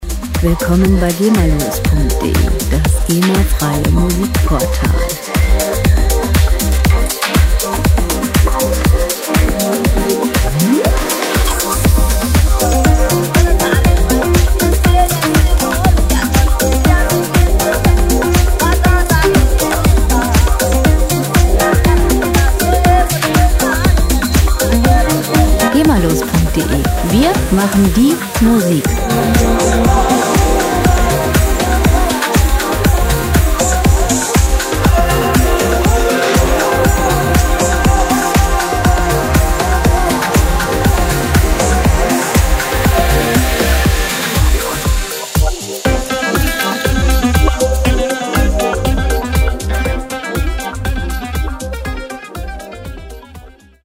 • Organic House